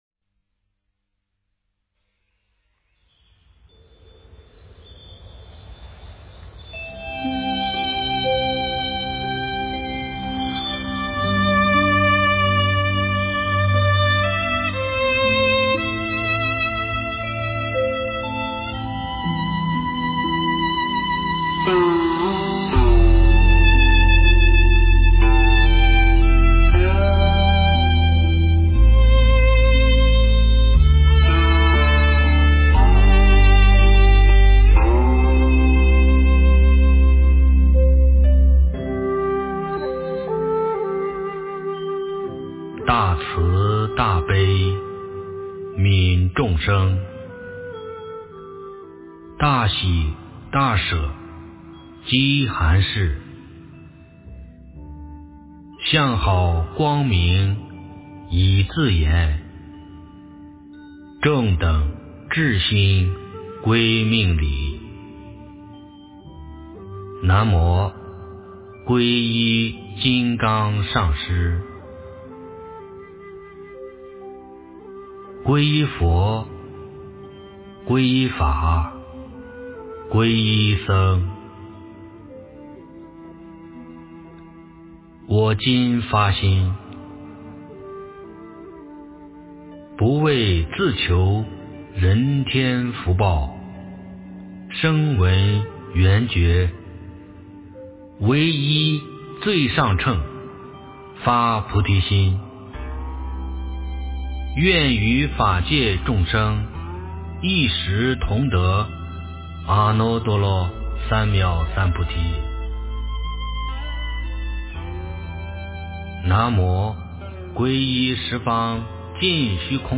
诵经
佛音 诵经 佛教音乐 返回列表 上一篇： 长寿佛经 下一篇： 佛说决定毗尼经 相关文章 观音菩萨在心中--黑鸭子 观音菩萨在心中--黑鸭子...